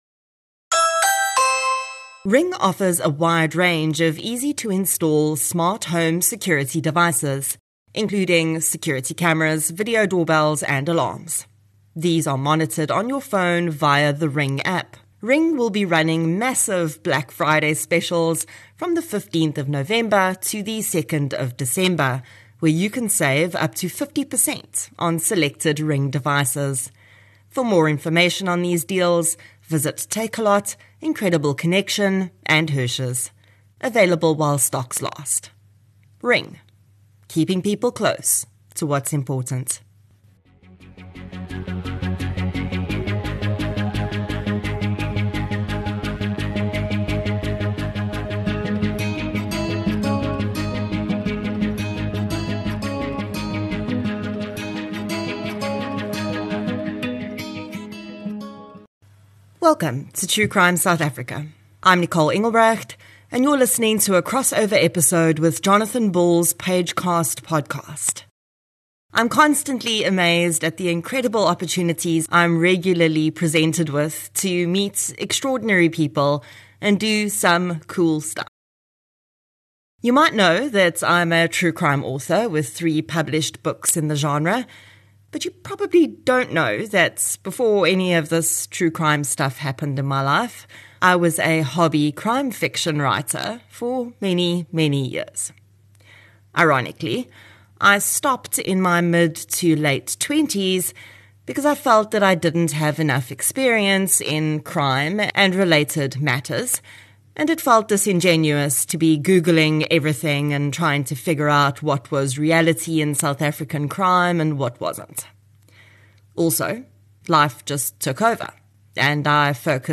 1 Interview - The Fact & Fiction of Crime in SA with Deon Meyer 46:17 Play Pause 1h ago 46:17 Play Pause Later Afspelen Later Afspelen Lijsten Vind ik leuk Leuk 46:17 In today's episode I have the incredible pleasure of chatting with one of the world's most successful crime fiction writers, Deon Meyer. Meyer's books have enthralled and captivated readers in South Africa for decades and our conversation reveals how that often entails getting closer to real crime than one might think.